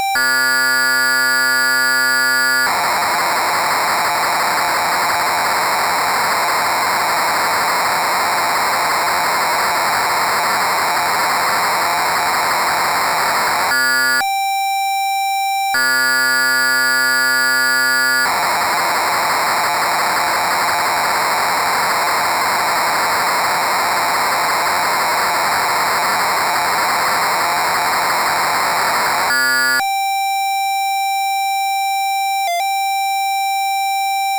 patch dump via tape interface in 48khz wave file